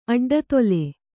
♪ aḍḍatole